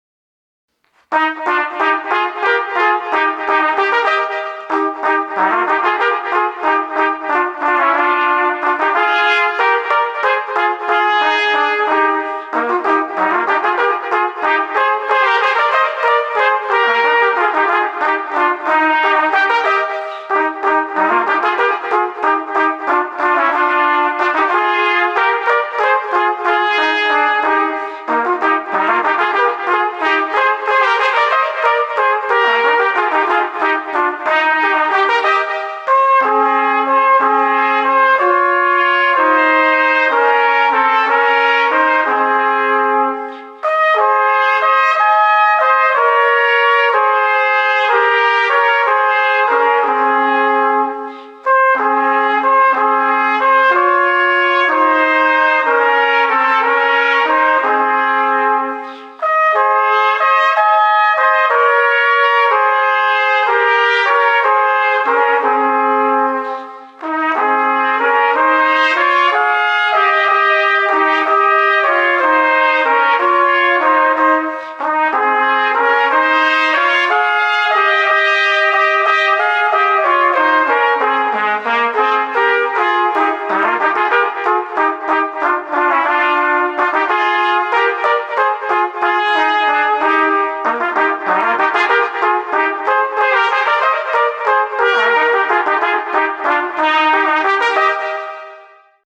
Gattung: für zwei Trompeten oder Flügelhörner